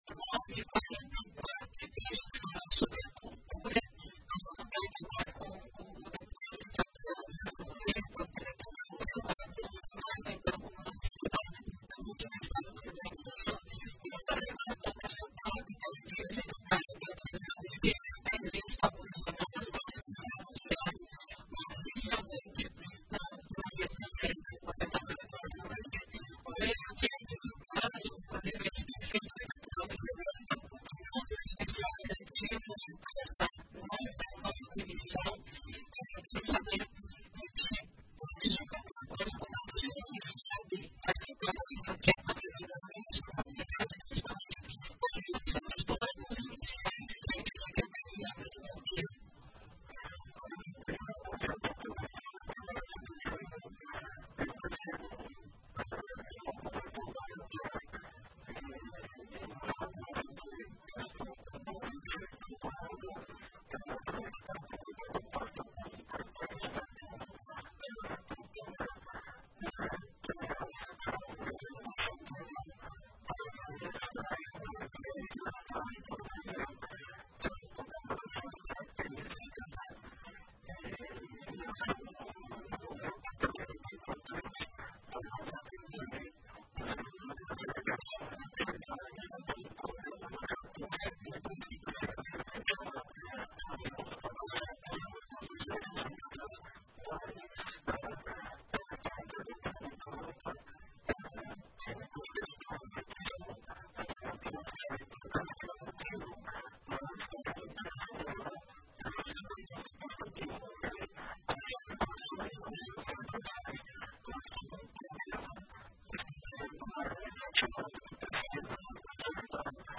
Em entrevista à RPI, Eduardo Loureiro cita os planos com a Secretaria de Cultura do RS